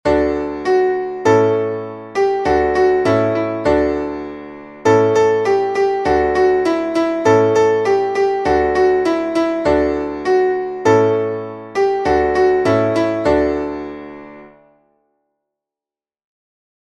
Traditionelles Winterlied